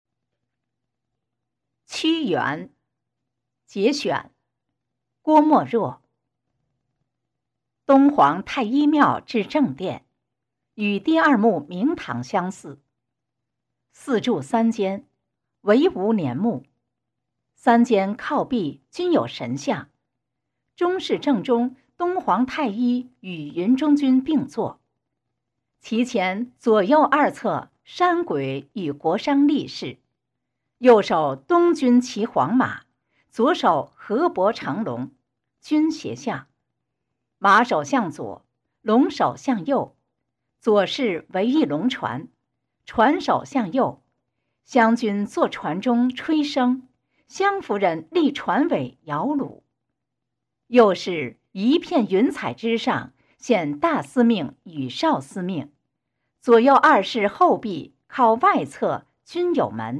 九年级语文下册 17《屈原（节选）》女声高清朗诵（音频素材）